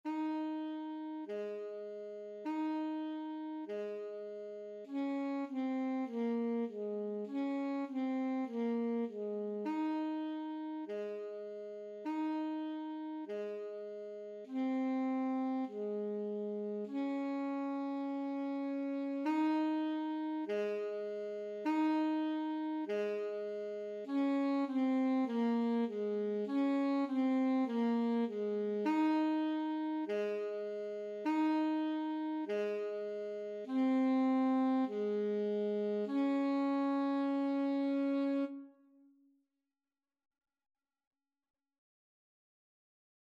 4/4 (View more 4/4 Music)
Ab4-Eb5
Saxophone  (View more Beginners Saxophone Music)
Classical (View more Classical Saxophone Music)